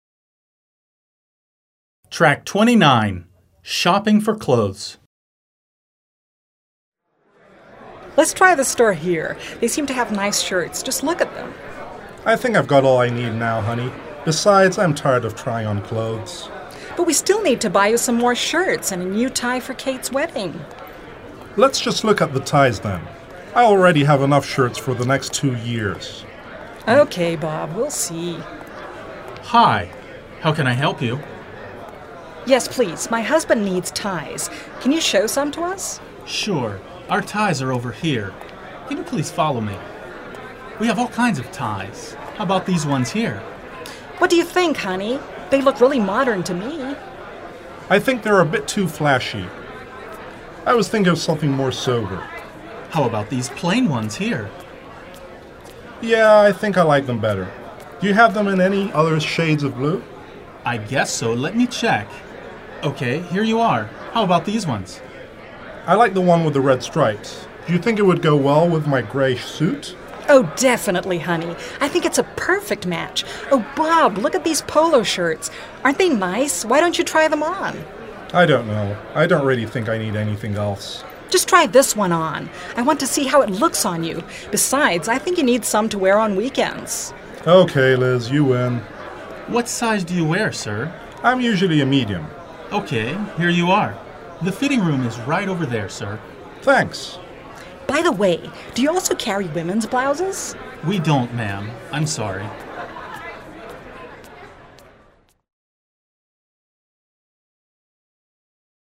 Click the link below to listen to the dialogue “Shopping for clothes” and then do the activities that follow.